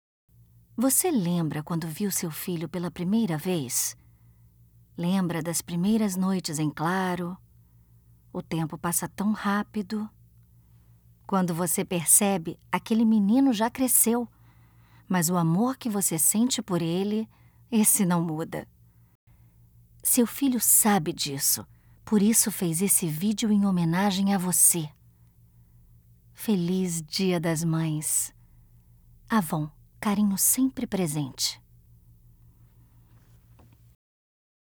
Feminino
Voz Jovem 00:34